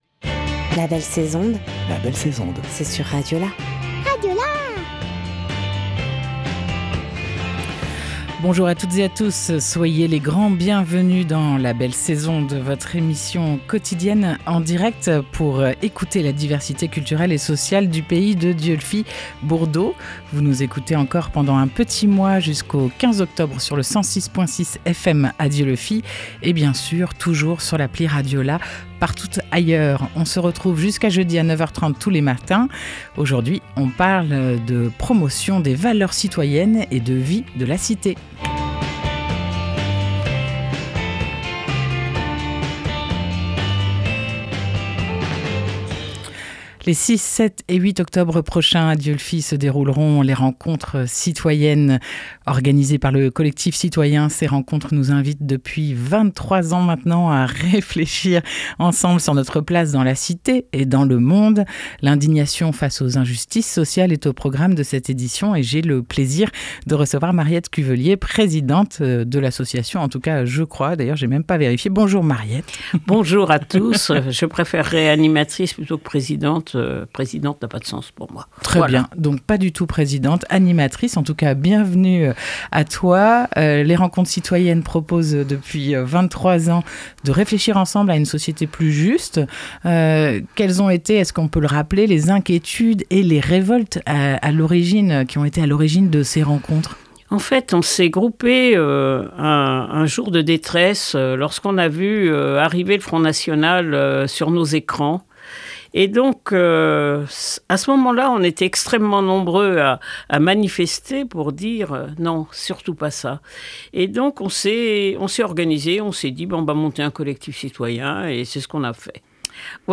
25 septembre 2023 11:31 | Interview, la belle sais'onde